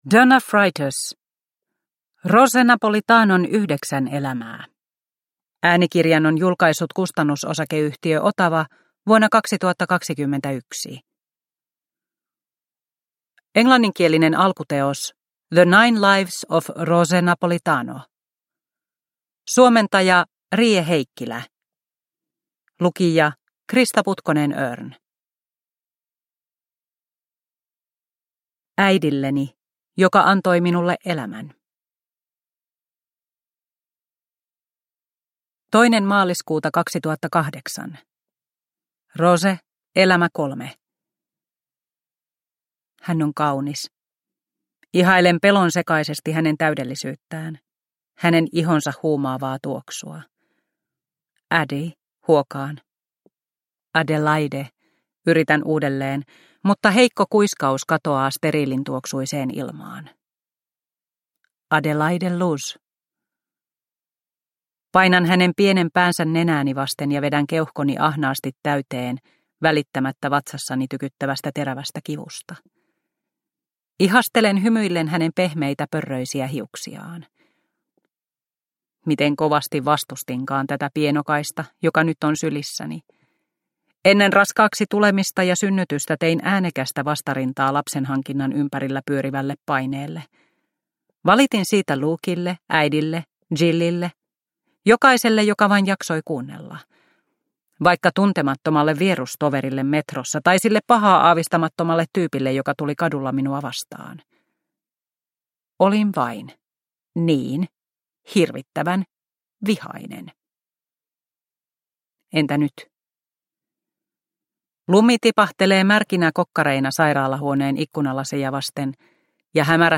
Rose Napolitanon yhdeksän elämää – Ljudbok – Laddas ner